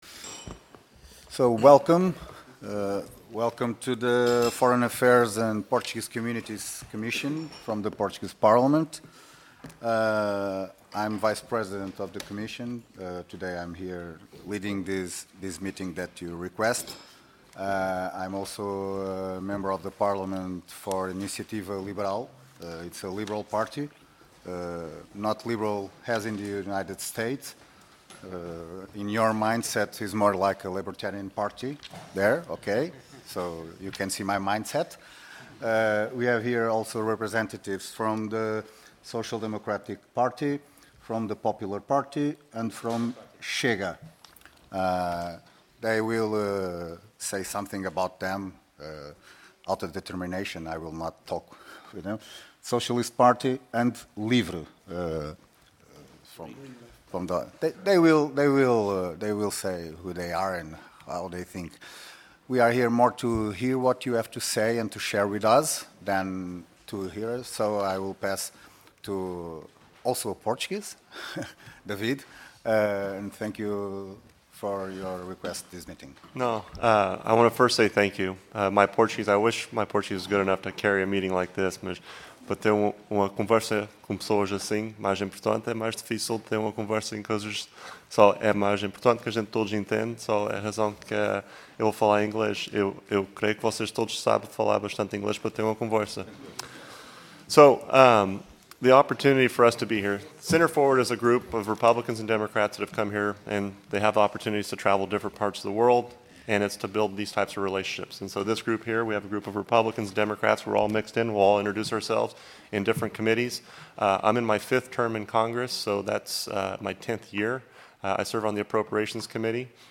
Comissão de Negócios Estrangeiros e Comunidades Portuguesas Audiência Parlamentar